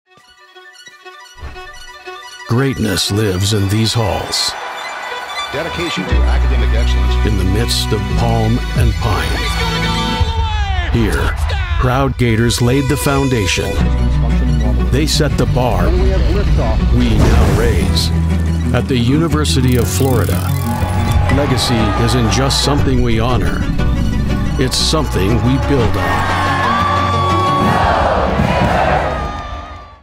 • Authentic and relatable — a real human presence, not a polished announcer veneer
• Gritty and grounded — forged in the mountains, with a modern Western edge
• Warm and trustworthy — the voice of a guide, not a salesman
Commercial Demo